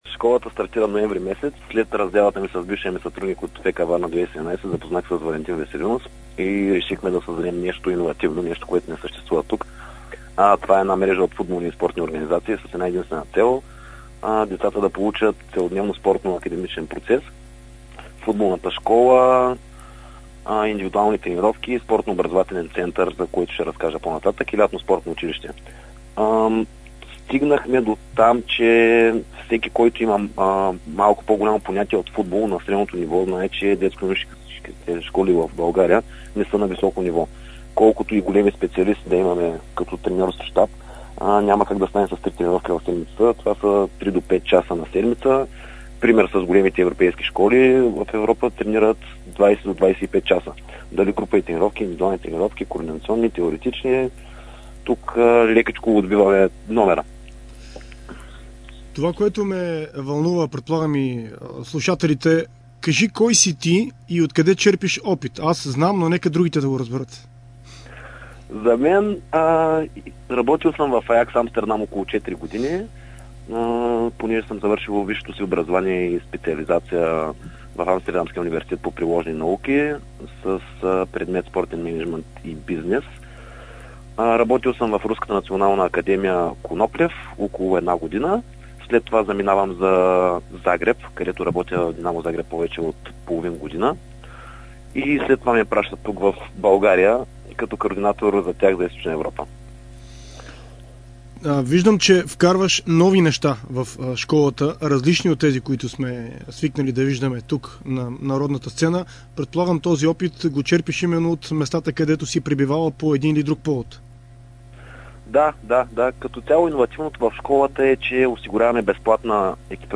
В интервю за Дарик радио и dsport